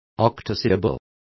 Complete with pronunciation of the translation of octosyllable.